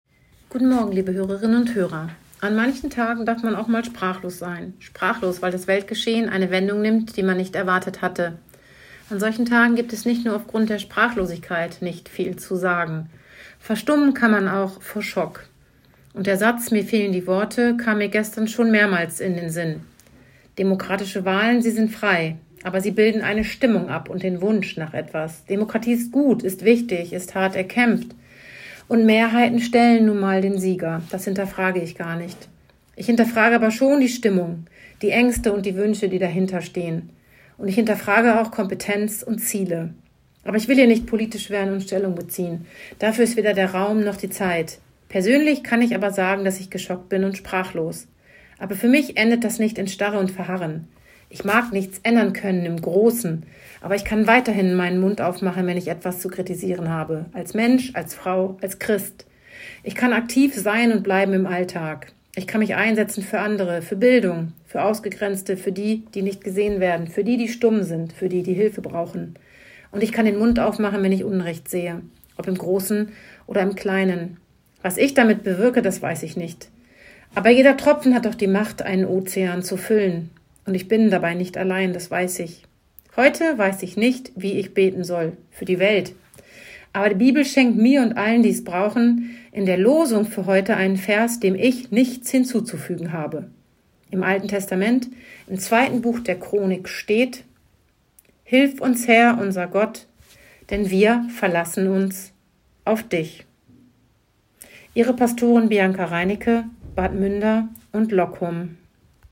Radioandacht vom 7. November